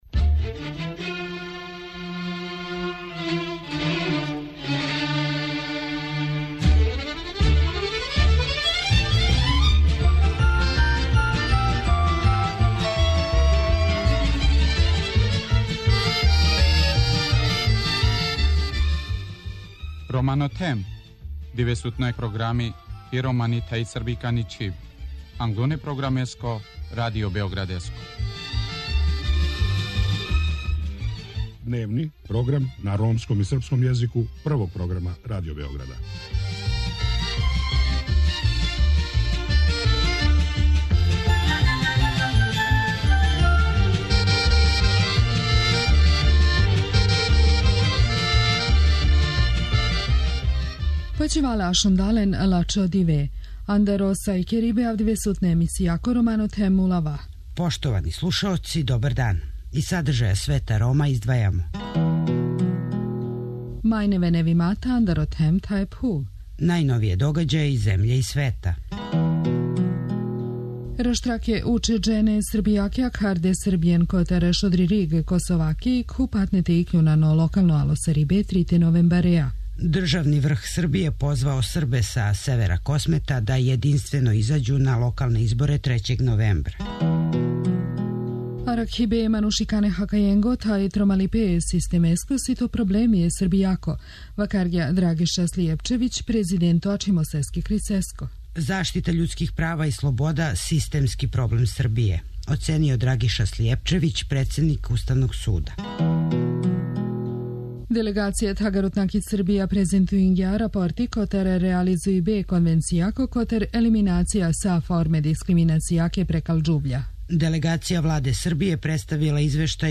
У Свету Рома преносимо део интервјуа са Галом, једним од најзначајнијих интелектуалаца средње Европе.